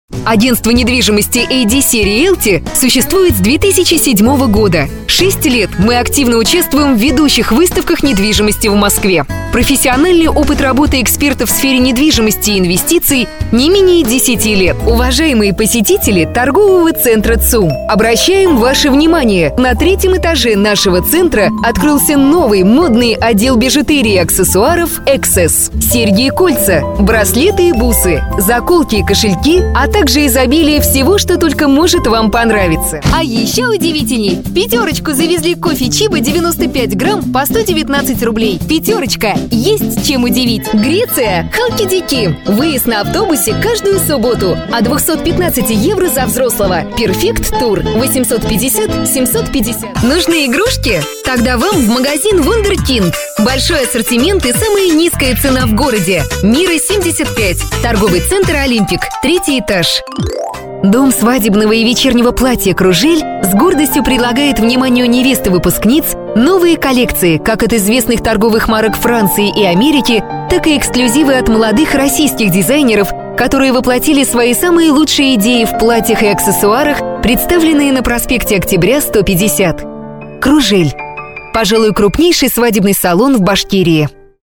Russian Voice Over